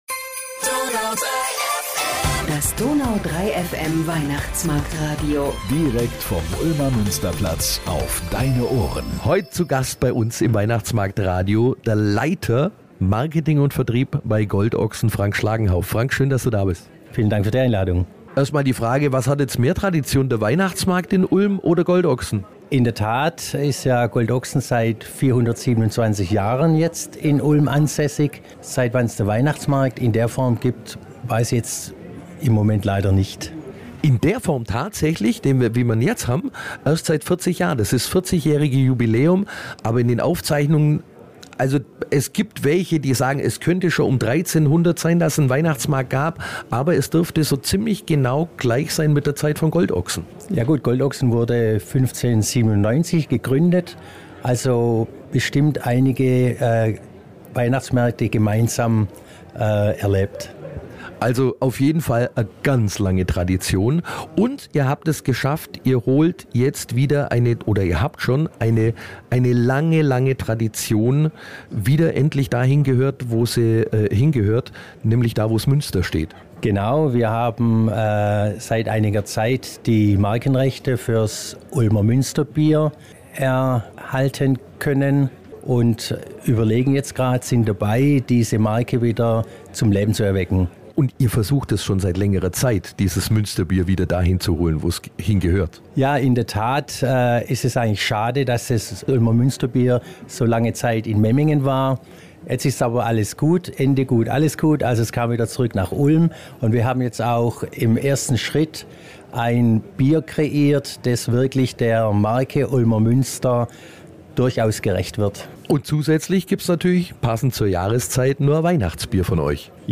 Direkt aus dem gläsernen Studio auf dem Ulmer Weihnachtsmarkt